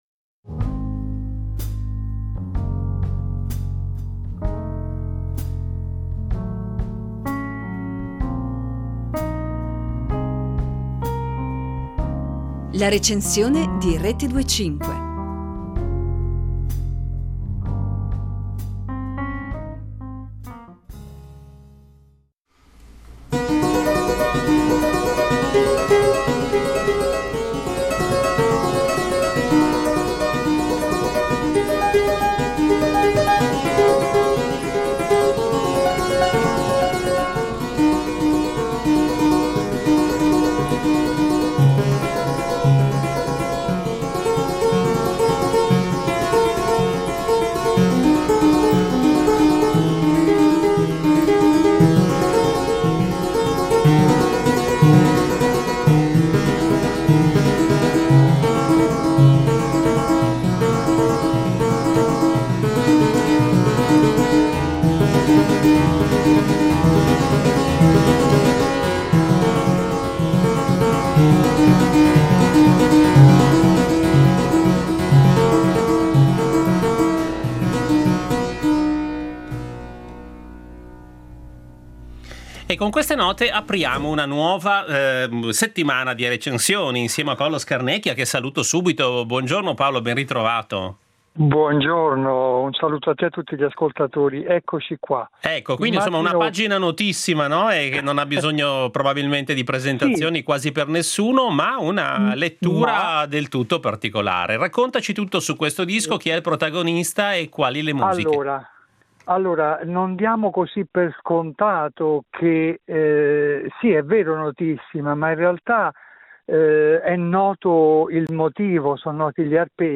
Per la loro registrazione Alard ha utilizzato due preziosi strumenti storici della famiglia Hass, un cembalo del 1740 e un clavicordo del 1763.